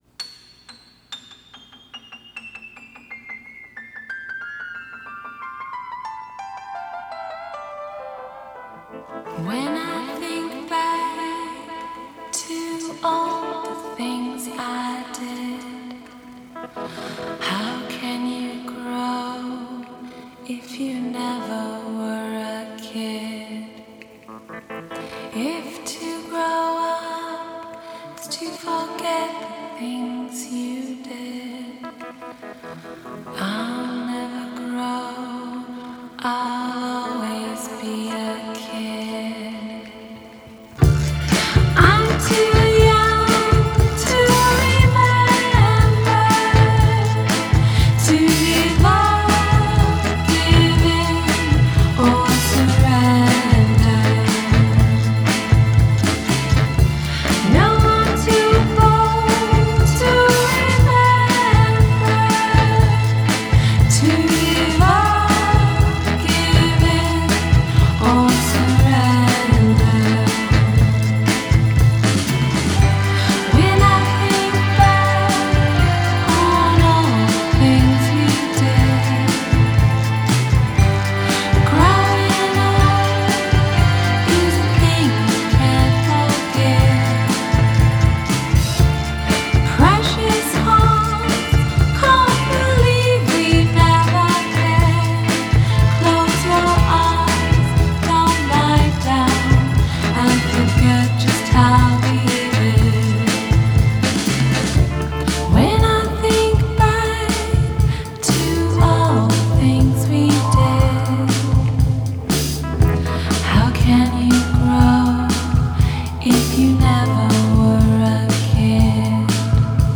minor key, faux-’60s romp
Los Angeles-based duo